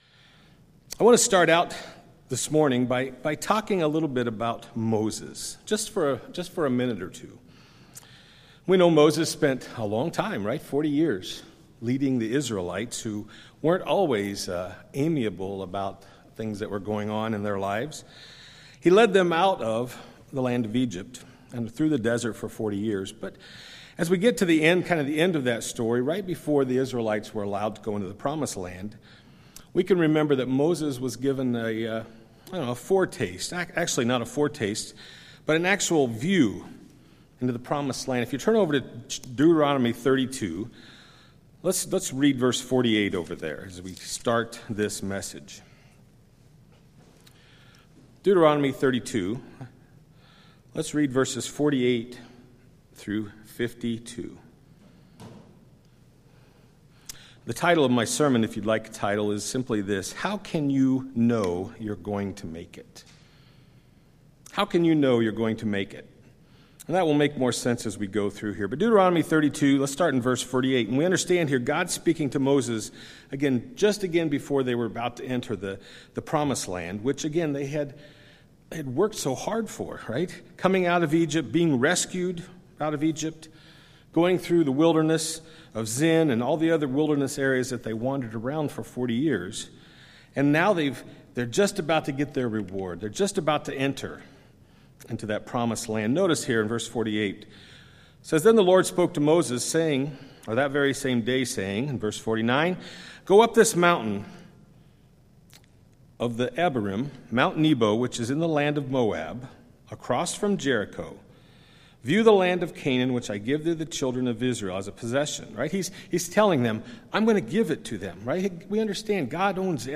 UCG Sermon Faith Grace mercy know your Bible promise obedience Studying the bible?